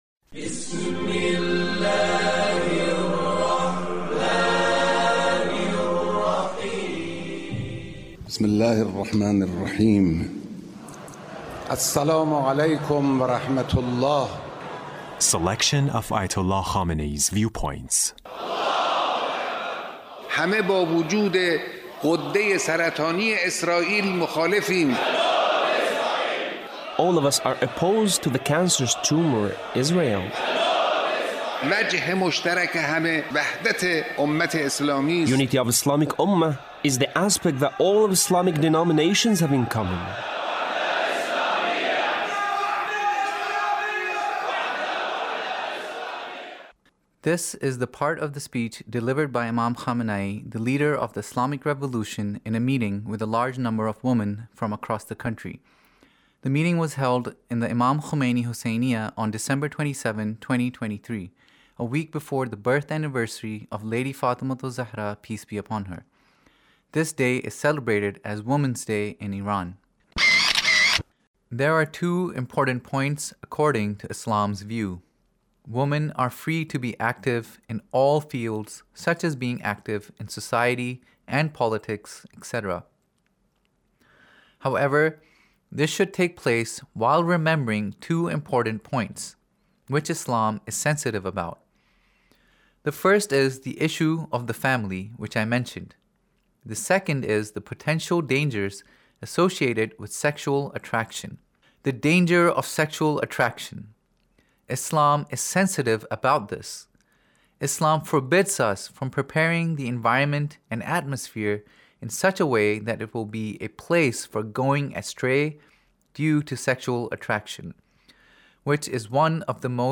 Leader's Speech in a meeting with Ladies on Women's Day